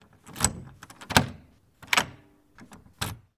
Lock Doorknob Push Button Sound
household